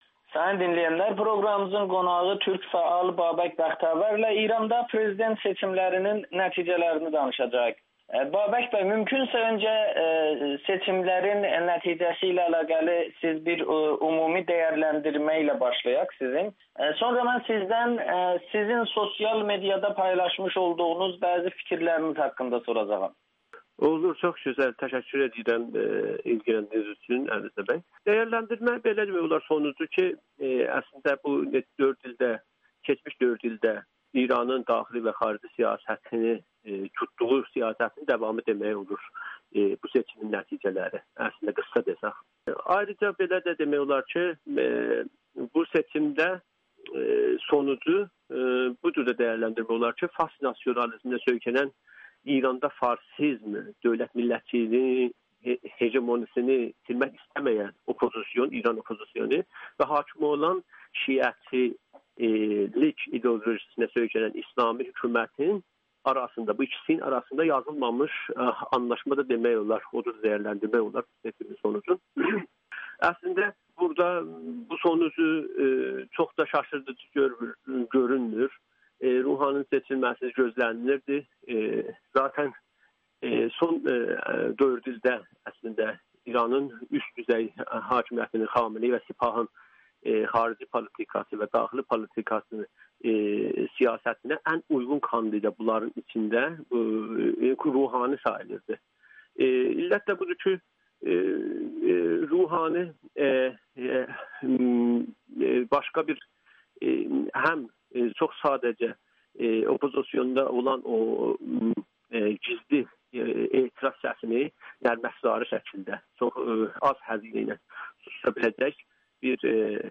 Ruhani İranın indiki xarici siyasətinin davamı üçün seçilməli idi [Audio-Müsahibə]